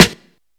Klt_Snr.wav